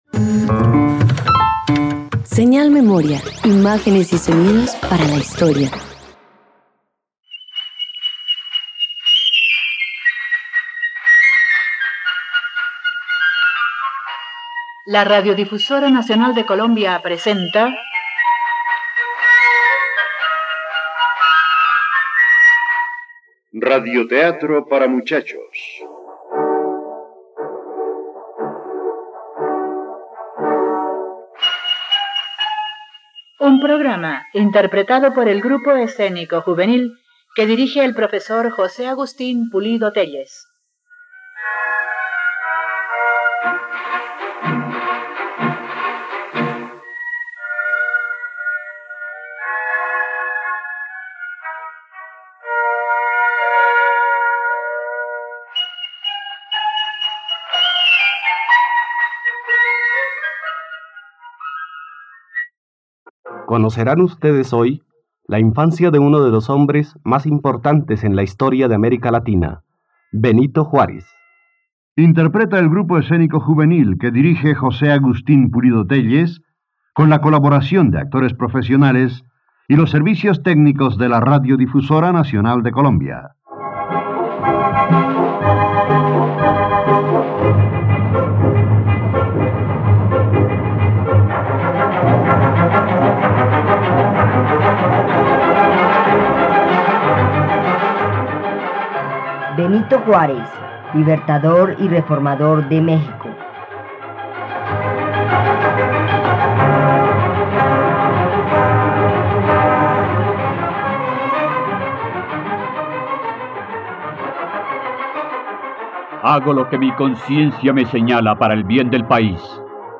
..Radioteatro.